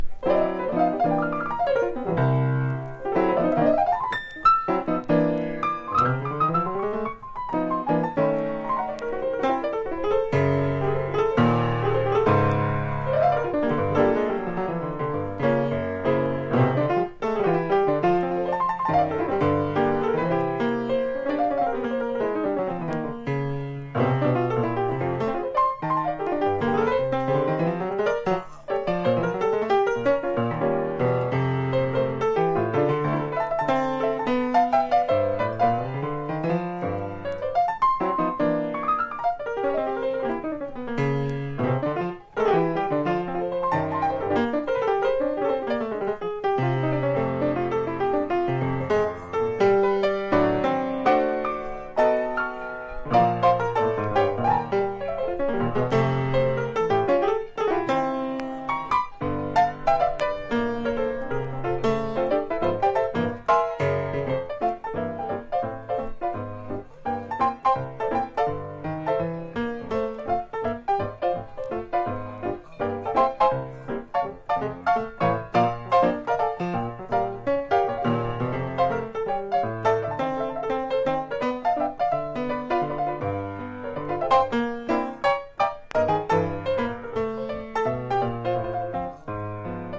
流石、父と呼ばれるだけある強力なソロ・プレイを収録!!(帯・解説付・RGP-1194)